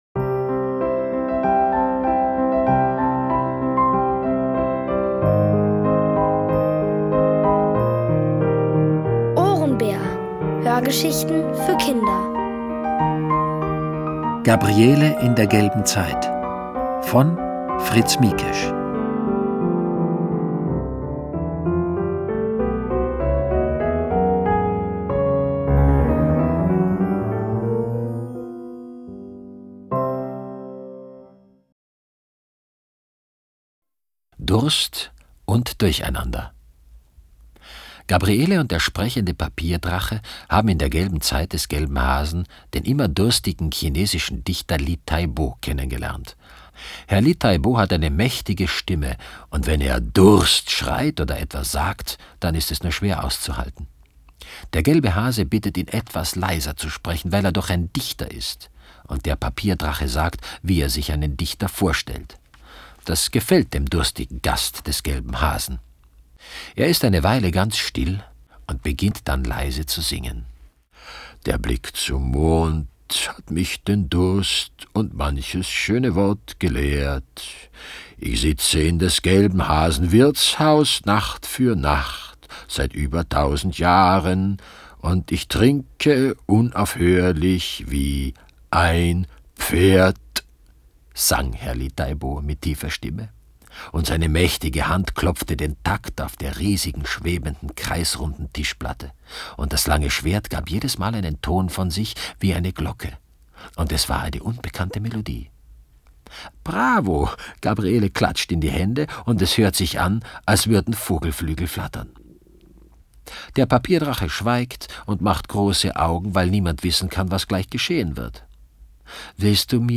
OHRENBÄR – das sind täglich Hörgeschichten für Kinder zwischen 4 und 8 Jahren.
Peter Simonischek.